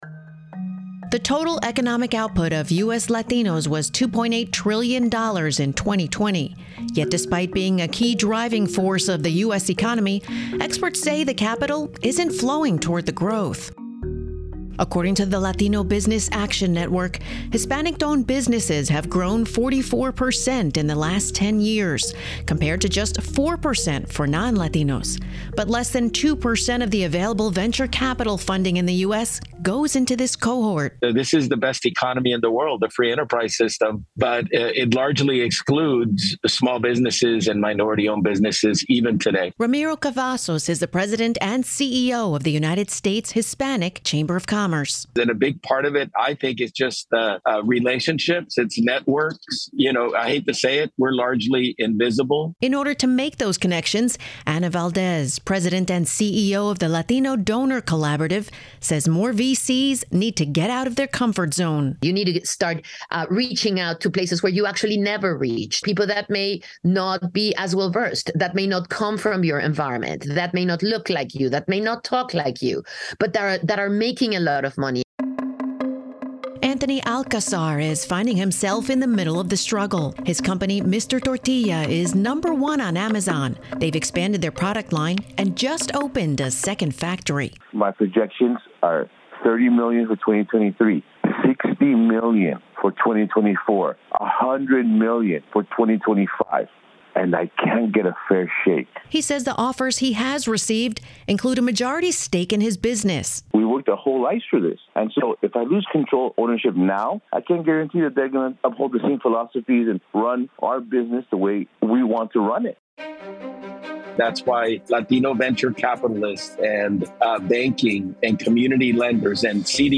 Special Report: Hispanic-Owned Business Investment (Audio)